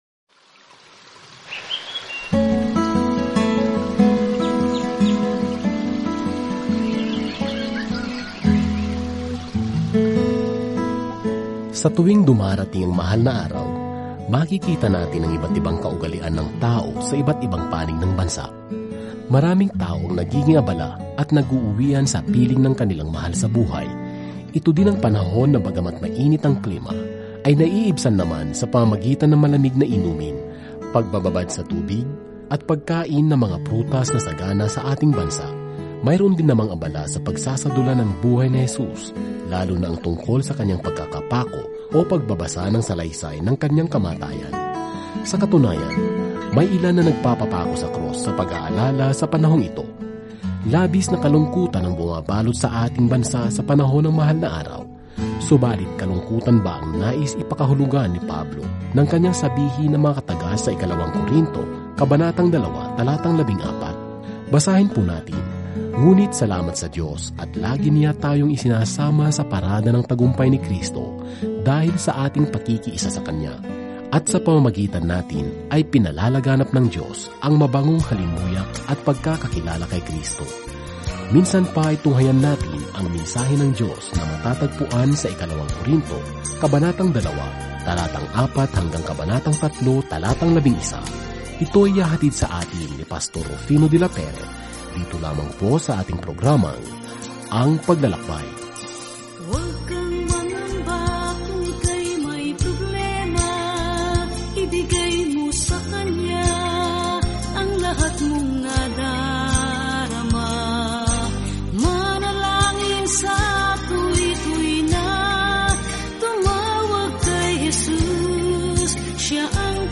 Araw-araw na paglalakbay sa 2 Corinthians habang nakikinig ka sa audio study at nagbabasa ng mga piling talata mula sa salita ng Diyos.